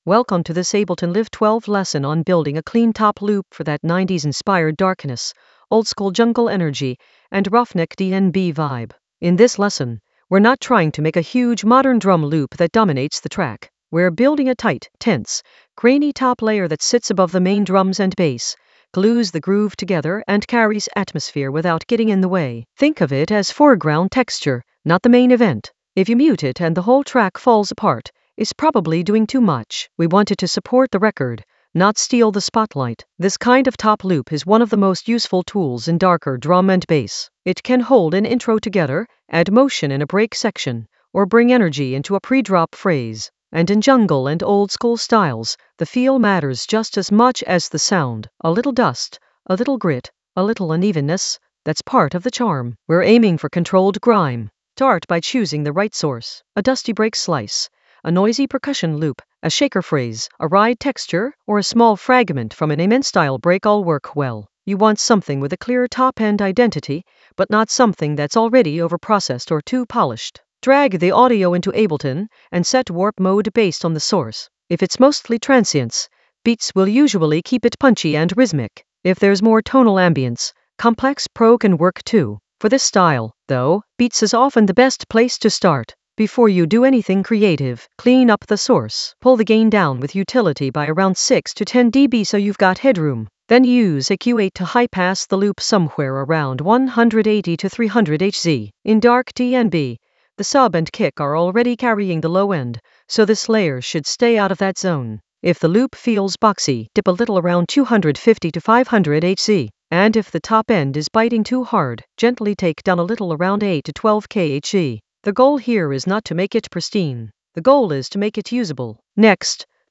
An AI-generated intermediate Ableton lesson focused on Ruffneck: top loop clean for 90s-inspired darkness in Ableton Live 12 for jungle oldskool DnB vibes in the Atmospheres area of drum and bass production.
Narrated lesson audio
The voice track includes the tutorial plus extra teacher commentary.